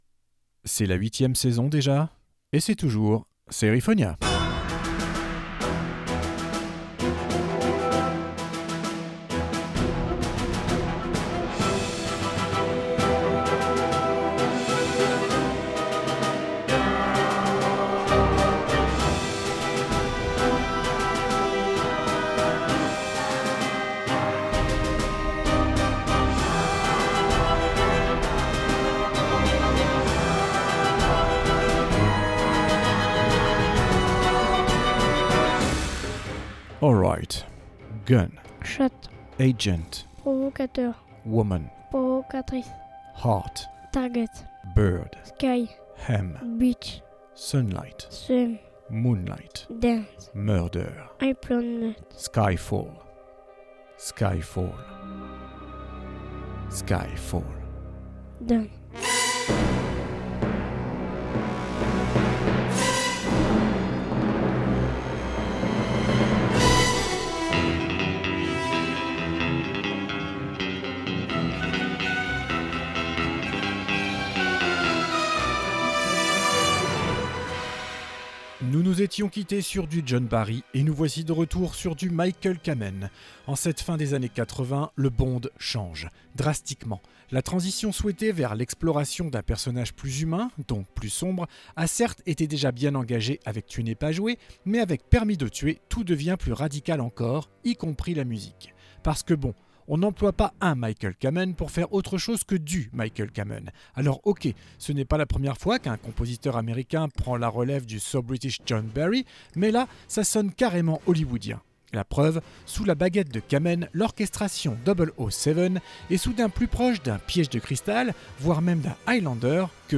SERIFONIA, SEASON 8 OPENING THEME